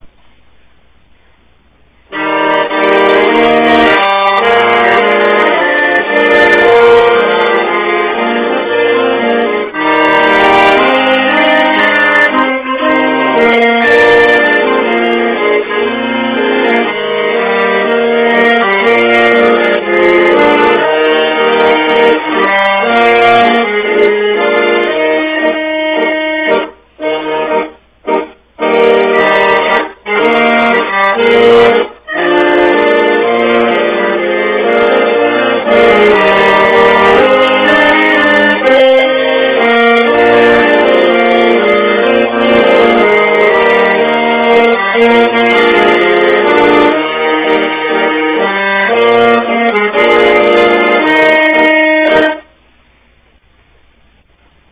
Click here to see a video of an accordion in action.